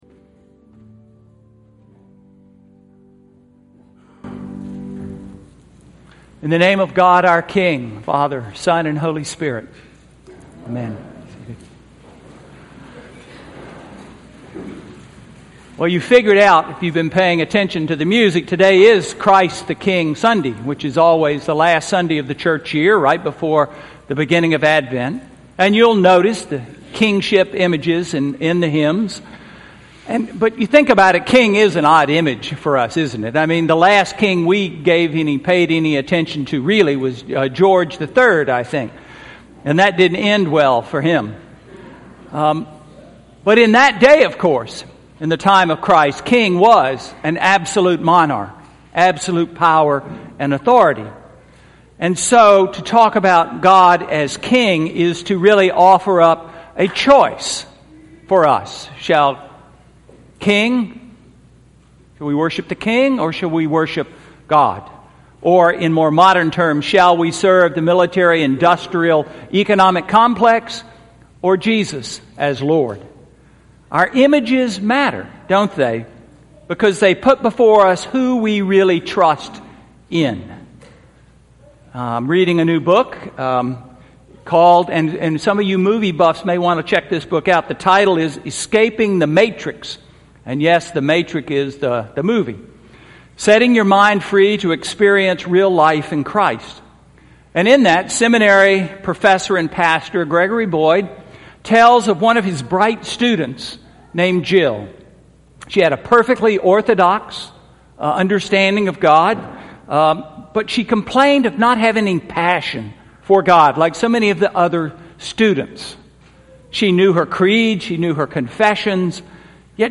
Sermon for Nov 24, 2013